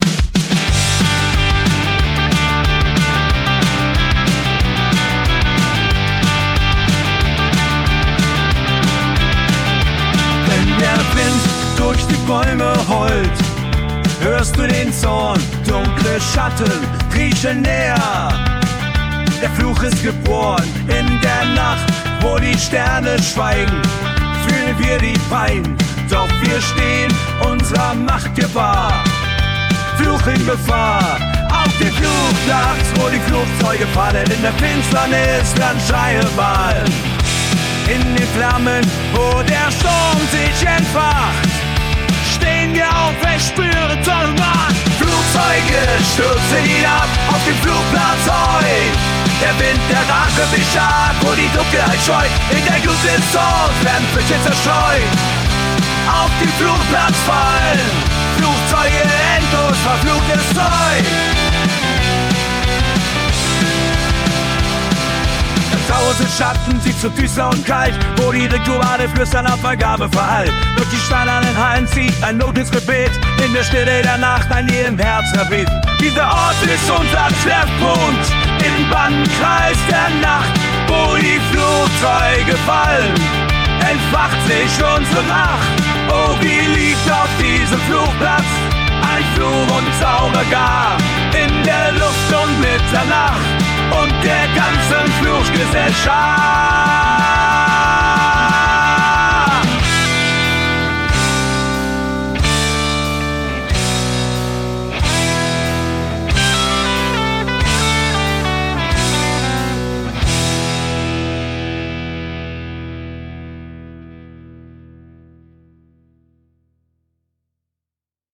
Projekt: KI-unterstütztes Punkalbum